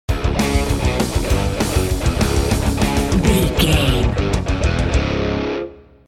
Epic / Action
Fast paced
Aeolian/Minor
C♯
Fast
drums
bass guitar
electric guitar